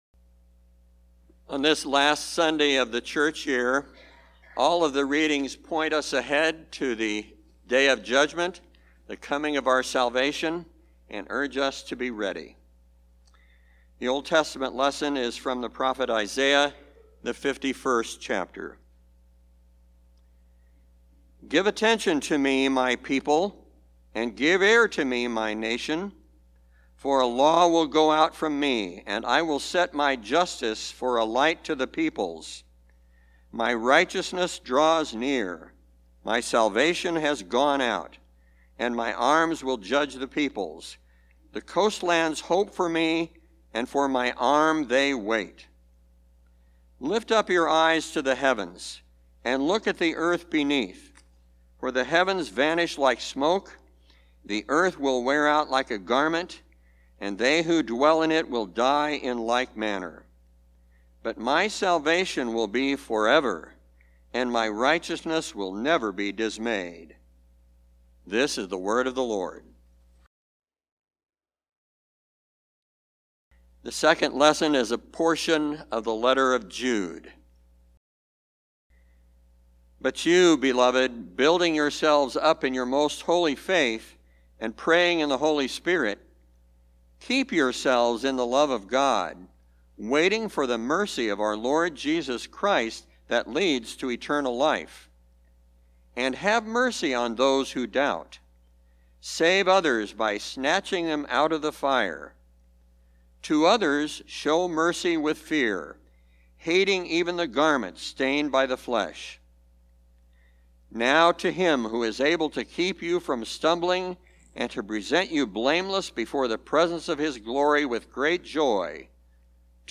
Even as we stay awake, we can rest in the promise that Christ our Lord is always with us. Audio Sermon Save Audio https